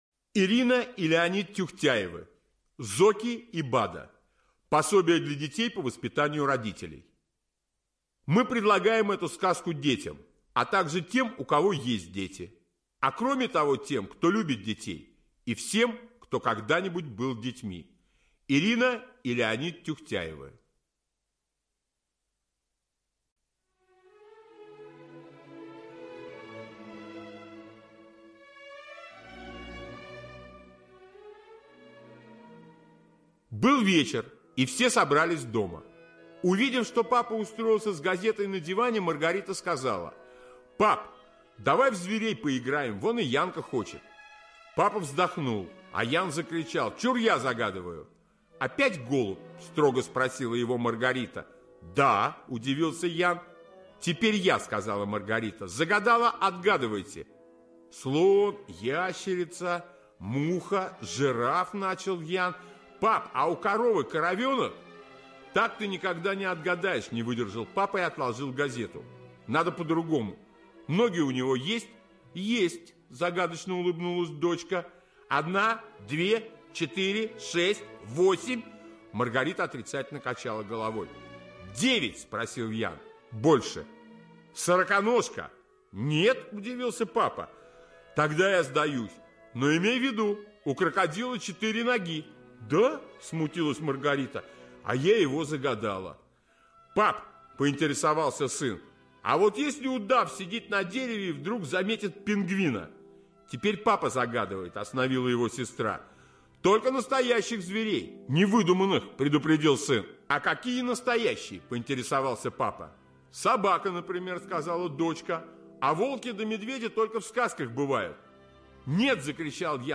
Зоки и Бада - аудиосказка - слушать онлайн